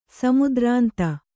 ♪ samudrānta